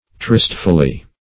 tristfully - definition of tristfully - synonyms, pronunciation, spelling from Free Dictionary Search Result for " tristfully" : The Collaborative International Dictionary of English v.0.48: Tristfully \Trist"ful*ly\, adv.